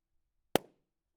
Pop Balloon 3
balloon bang burst pop popping sound effect free sound royalty free Memes